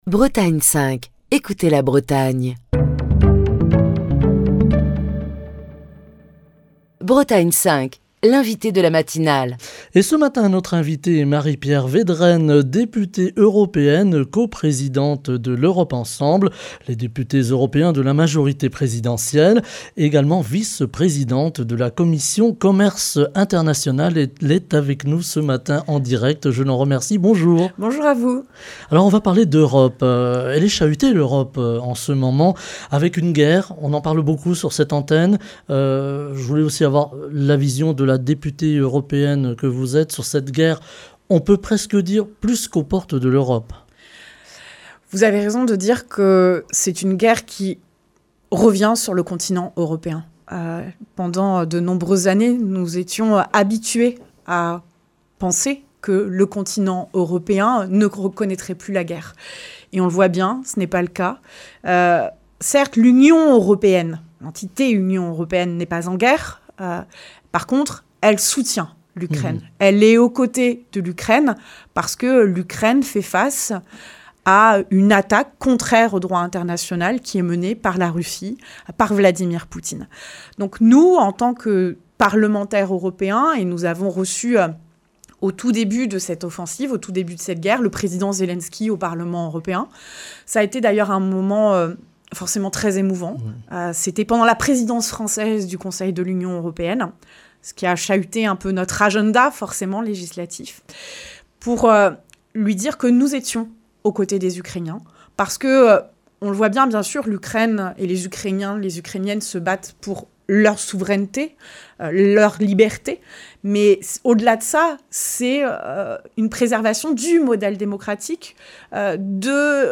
L'Union européenne qui fait face à la guerre en Ukraine, sur fond de crise économique et de montée des extrêmes, est à un tournant de son histoire. Les enjeux sont multiples pour l'Europe, tant sur le plan stratégique, politique, économique et social, que sur celui de l'énergie de l'environnement et du climat. Ce jeudi on parle de l'Europe avec Marie-Pierre Vedrenne, députée européenne, coprésidente de L’Europe Ensemble (députés européens de la majorité présidentielle), vice-présidente de la commission Commerce international, qui est l'invitée de la matinale de Bretagne 5.